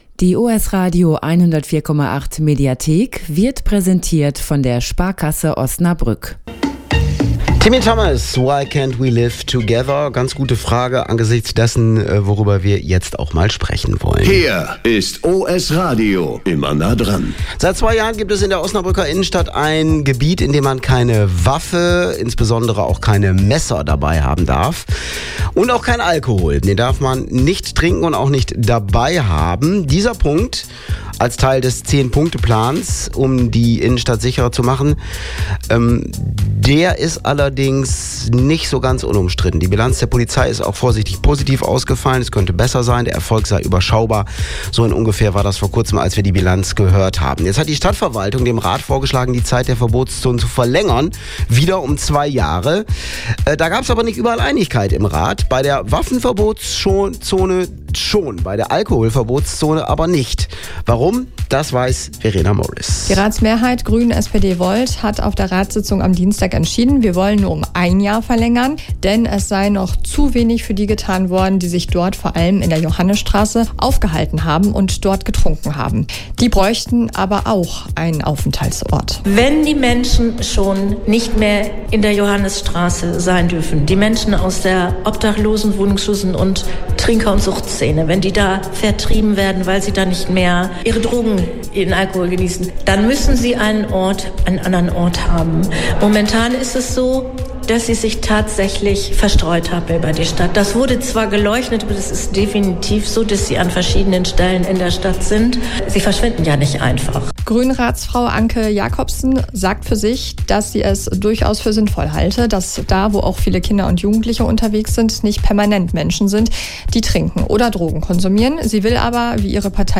Die Waffenverbotszone wird weitergeführt, bei der Alkoholverbotszone geht die Ratsmehrheit aus Grünen, SPD und Volt einen anderen Weg: Sie wird zunächst nur um ein Jahr verlängert. In dieser Zeit soll ein Konzept entstehen, das einen alternativen Aufenthaltsort für Menschen schafft, die bisher aus der Verbotszone verdrängt werden. Warum die Grünen hier Druck machen, welche Ideen es für solche Orte gibt und wie die Maßnahmen insgesamt bewertet werden, erklärt Grünen-Ratsfrau Anke Jakobsen im Mitschnitt.